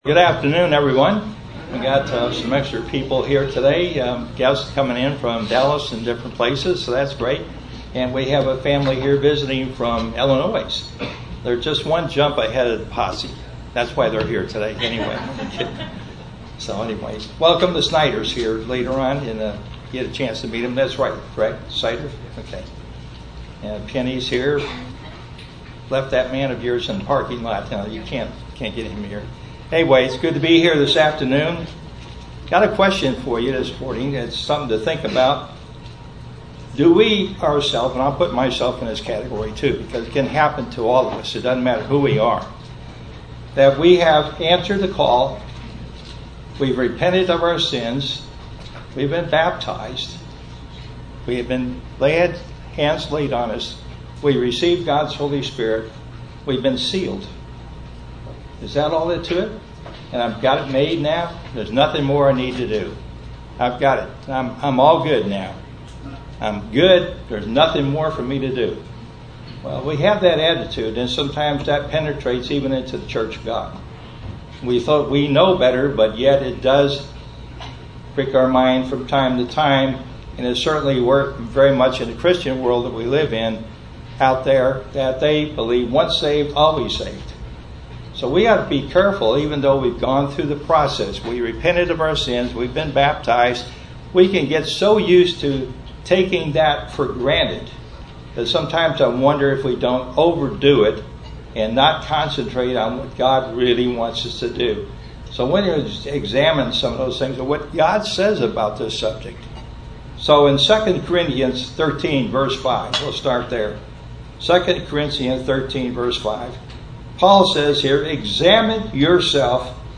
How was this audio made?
Given in Fort Worth, TX